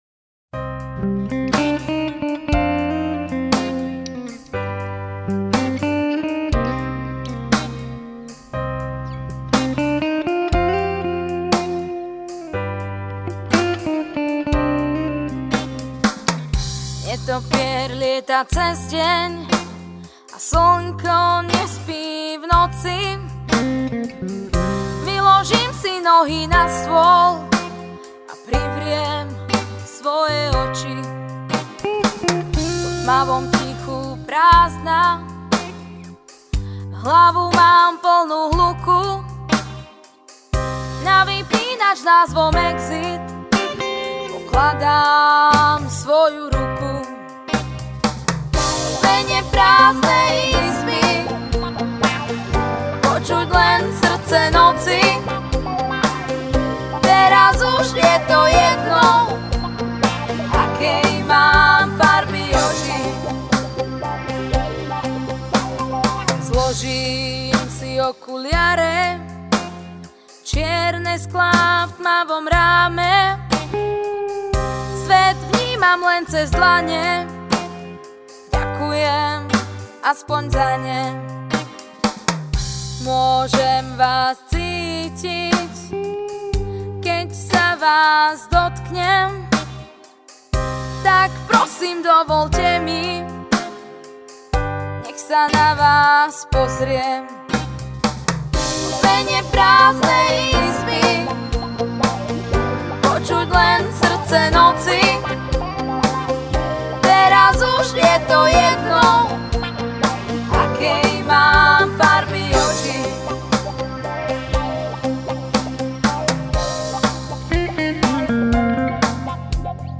Malá ukážka vytvorená ako pokus o popík  v domácom štúdiu, tomu zodpovedajú texty s tematikou srdečných záležitostí/bolesti duše spôsobené láskou/ cool
Z hlediska zvuku mi (hlavně v Netopýrovi) přijde, že jsou tam moc ostré a nepříjemné výšky (ale nemám žádné referenční sluchátka).
Spev je agresívny a niečo s tým určite bude treba poriešiť, ale našťastie je to len demo. To, že sa nahrávalo doma sa prejavilo.